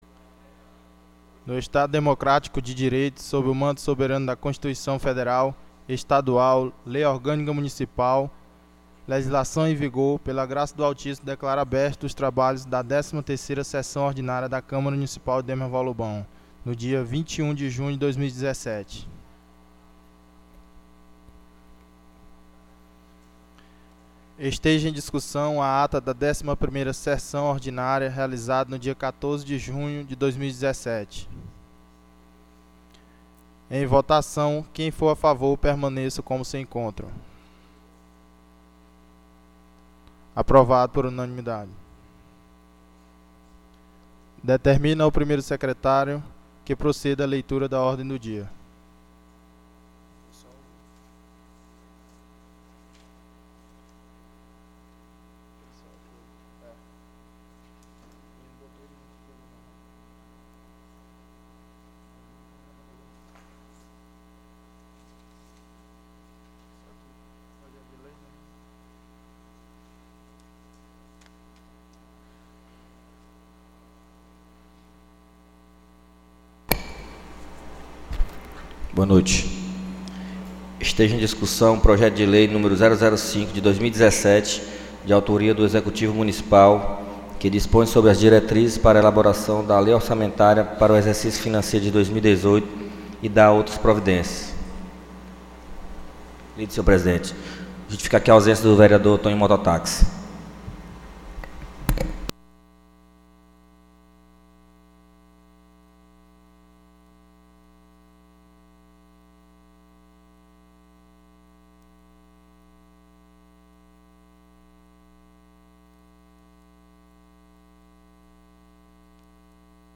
13ª SESSÃO ORDINÁRIA 21/06/2017